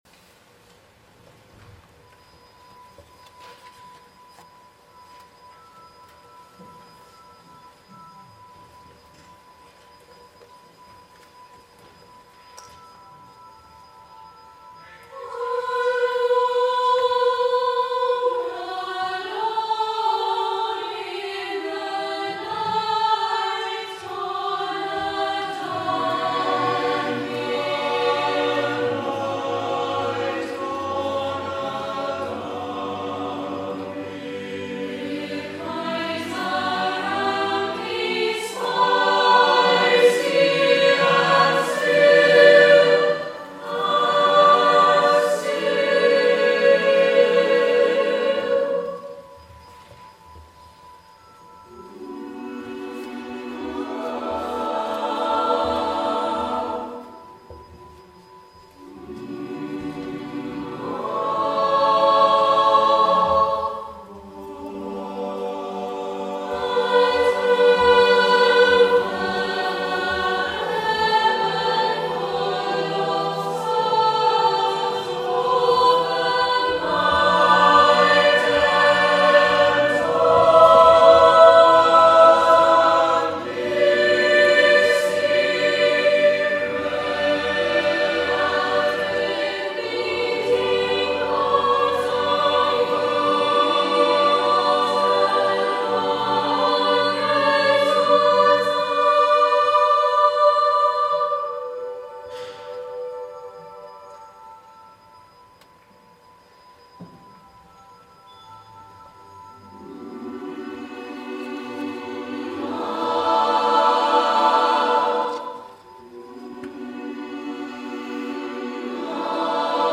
Chamber Choir Reaches National Finals
Following our St Cecilia concert, the choir was entered into Barnardo’s National Choral Competition, and we are delighted to announce that we have been selected as finalists.
here - and the lush harmonies throughout.